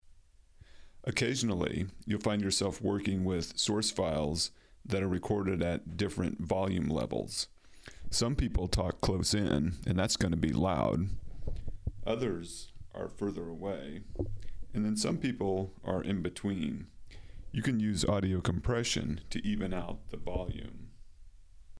this audio file I used the function "Dynamic processing" in Adobe Audition to level out the volumes.
unevensoundlevel.mp3